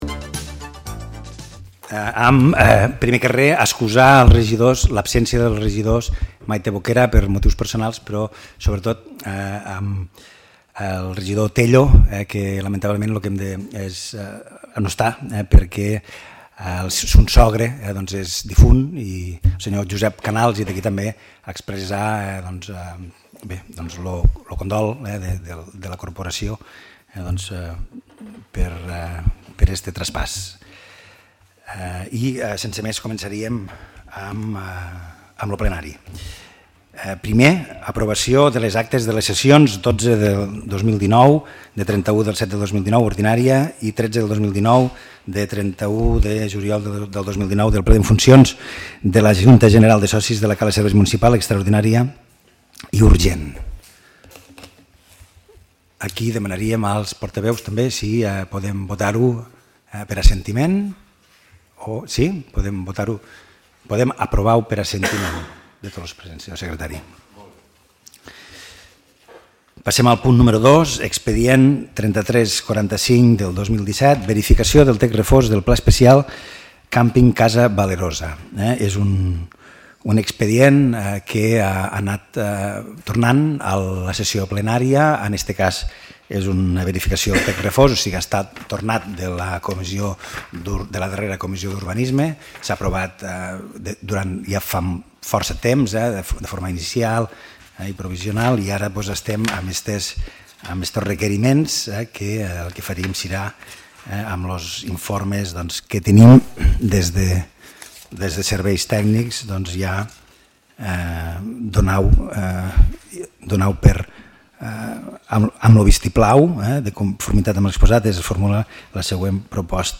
Ple ordinari (30/09/2019)